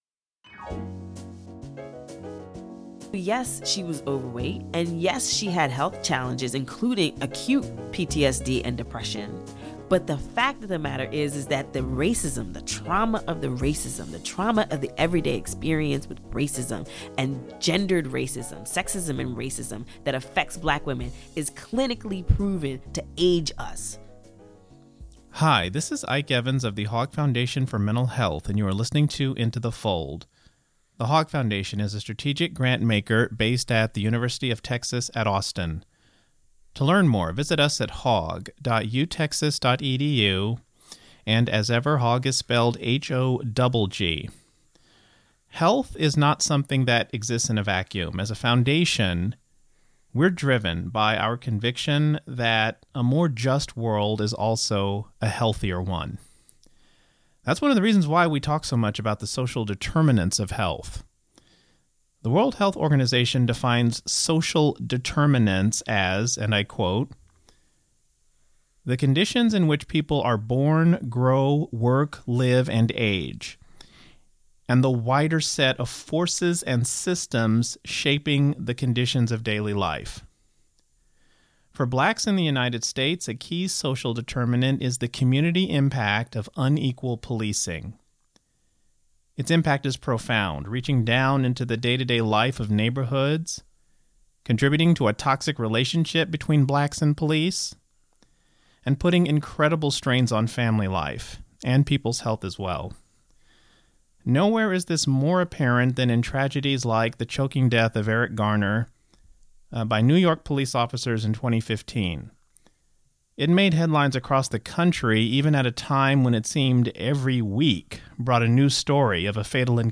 a wide-ranging conversation